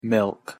LAxuqhZsHTo_pronunciation-en-milk.mp3